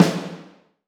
ORCH SNSFT-S.WAV